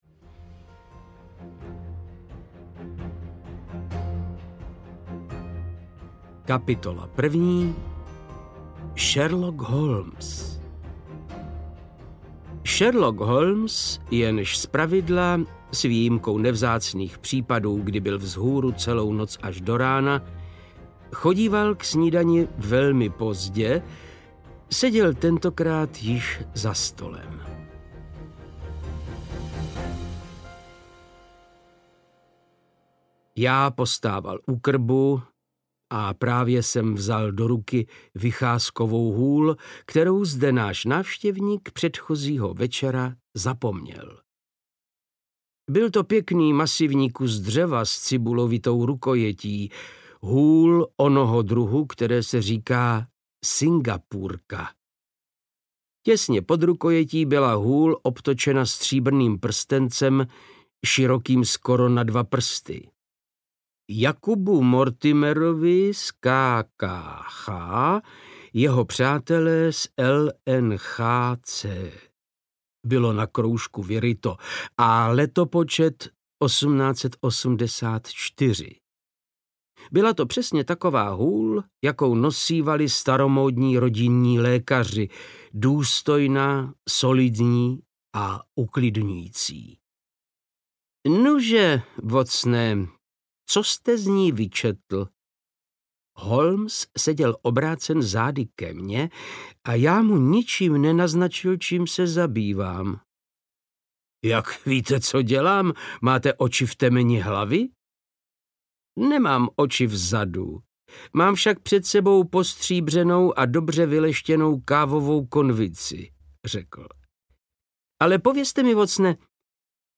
Pes baskervillský audiokniha
Ukázka z knihy
• InterpretVáclav Knop